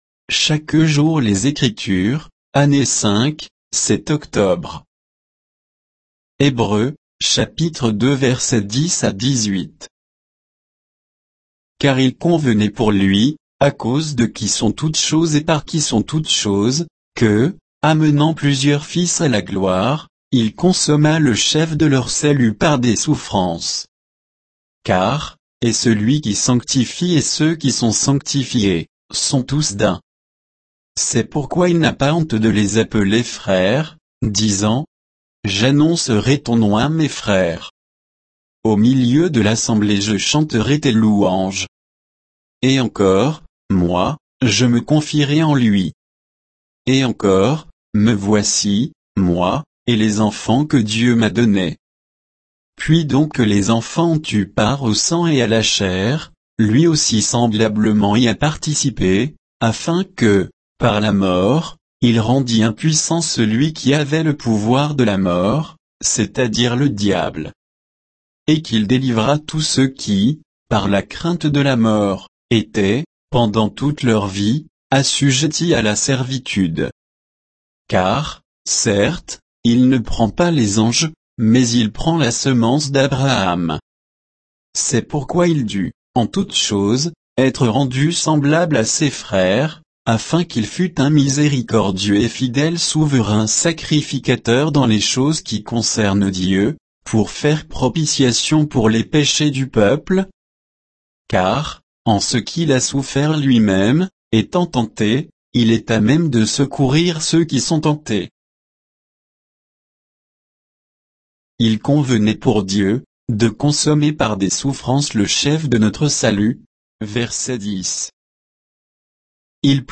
Méditation quoditienne de Chaque jour les Écritures sur Hébreux 2, 10 à 18